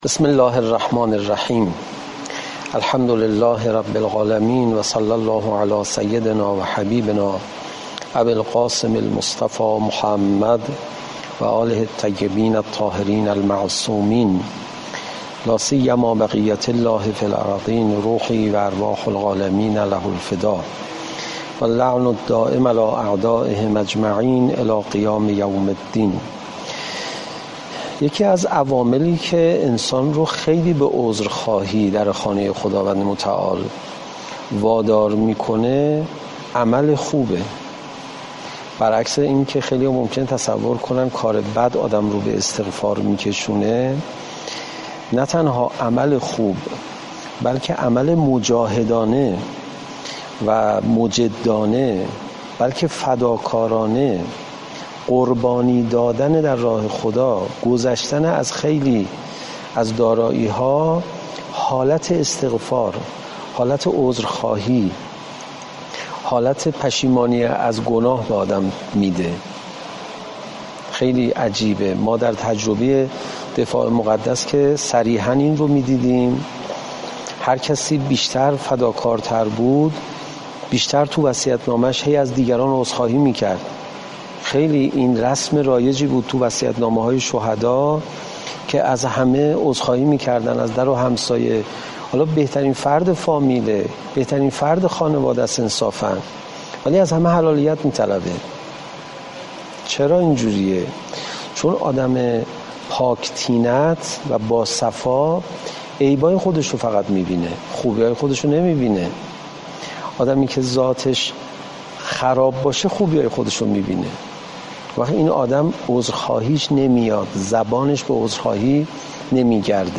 صوت سخنرانی استاد پناهیان